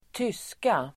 Ladda ner uttalet
tyska.mp3